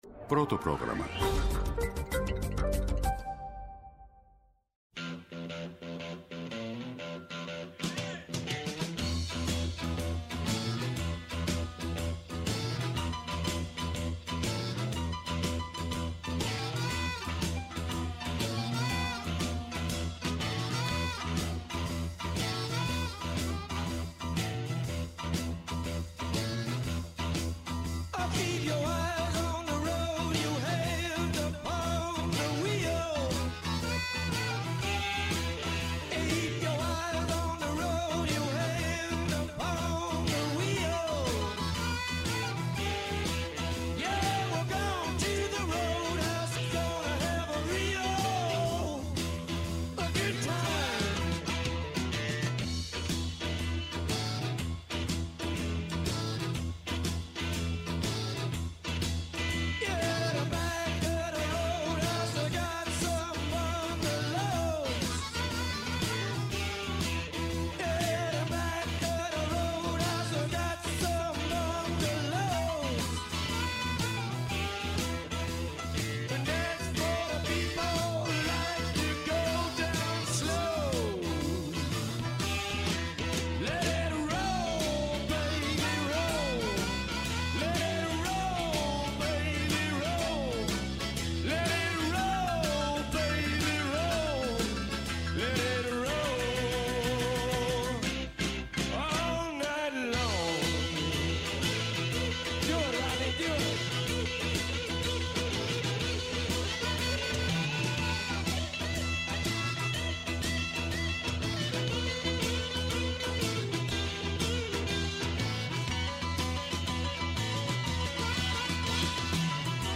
Καλεσμένος σήμερα είναι ο Γιάννης Πανούσης, πρώην πρύτανης στο Δημοκρίτειο Πανεπιστήμιο Θράκης και πρώην υπουργός.